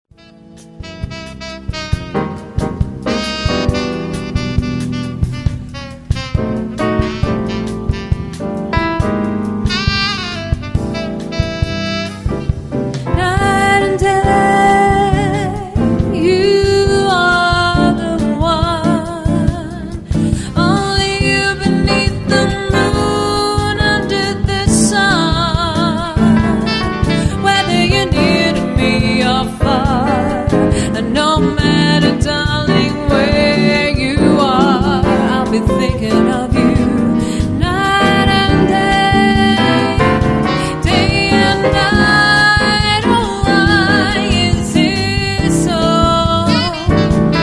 Swing & Jazz